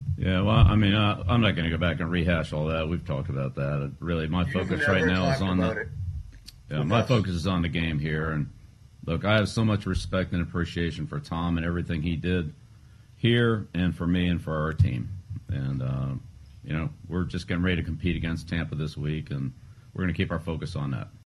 During a press conference on Monday, Belichick faced multiple hard-hitting questions about Brady’s return. He avoided answering these questions by stating he had already addressed the topic, which is false as he has yet to speak about Brady’s first time playing in Foxborough for another team.